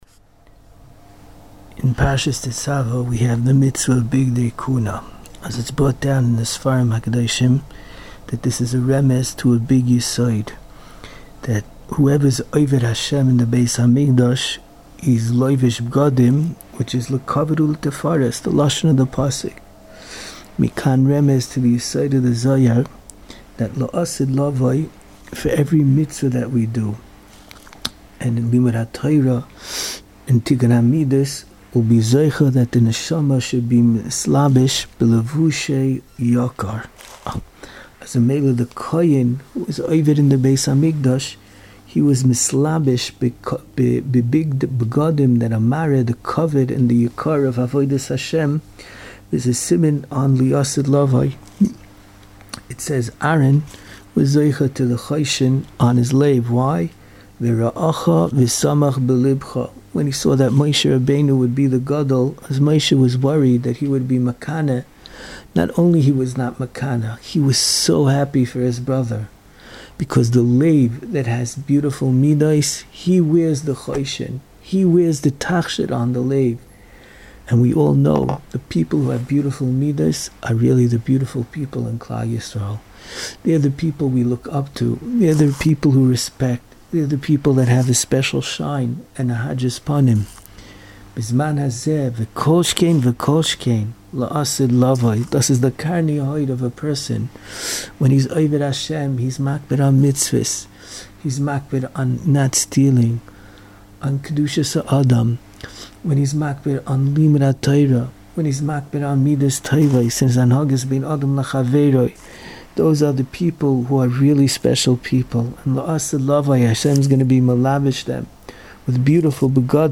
Inspiring Divrei Torah, Shiurim and halacha on Parshas Tetzaveh from the past and present Rebbeim of Yeshivas Mir Yerushalayim.